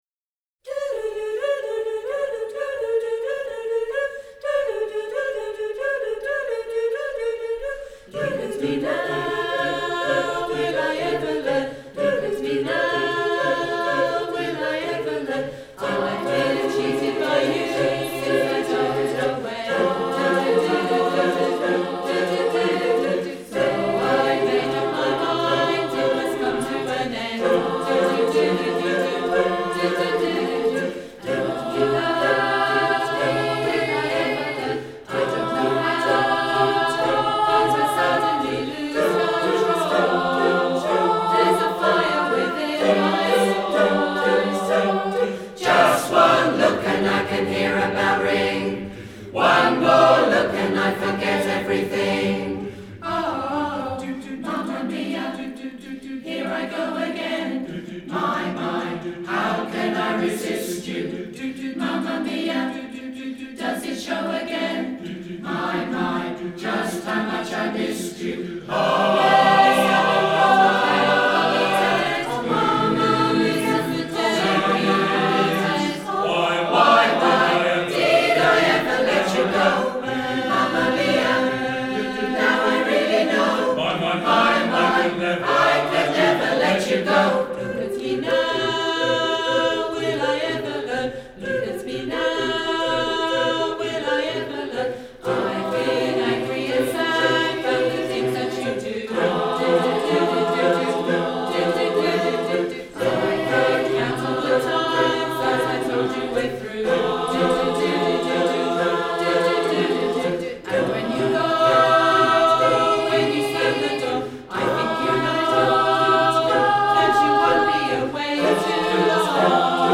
Mama Mia | Ipswich Hospital Community Choir